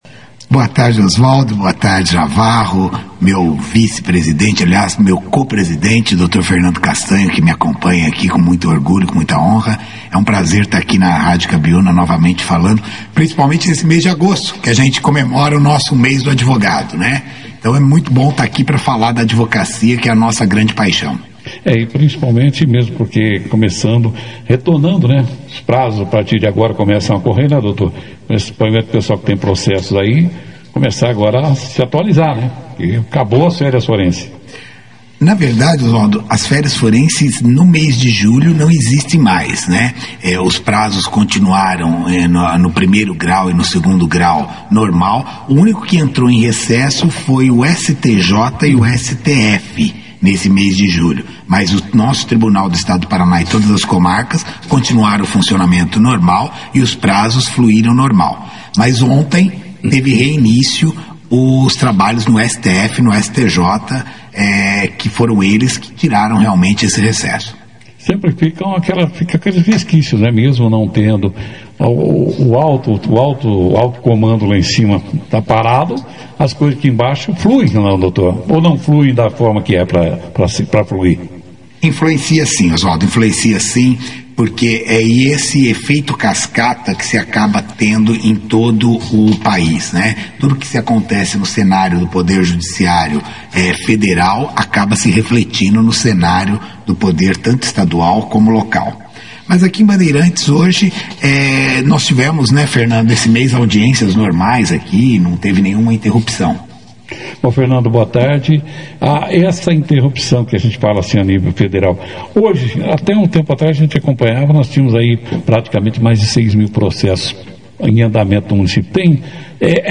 Durante a entrevista